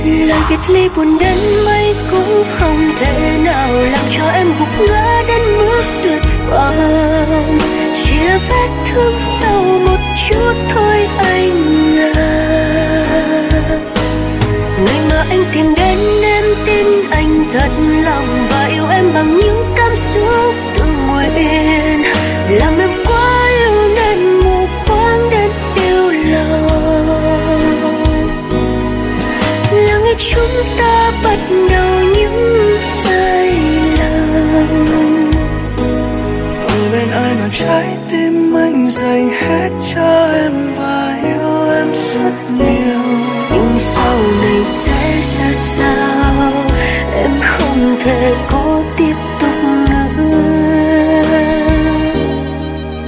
Nhạc Trẻ.